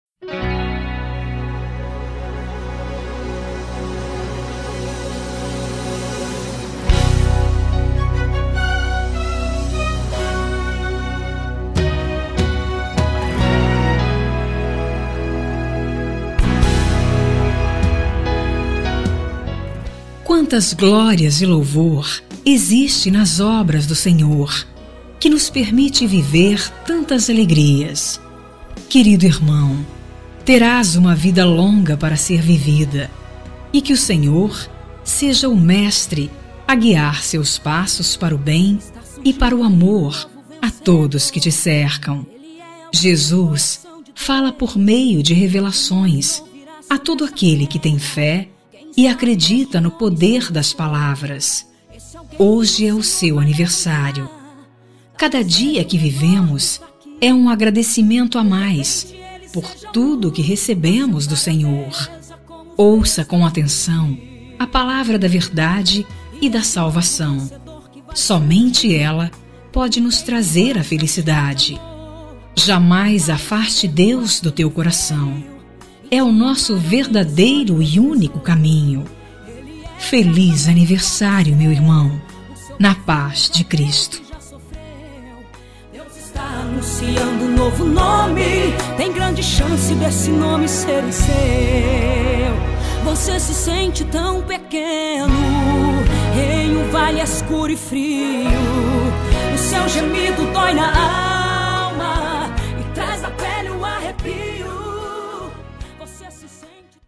Telemensagem de Aniversário de Irmão – Voz Feminina – Cód: 202230 – Evangélica
05- IRMÃO FEM GOSPEL 1.mp3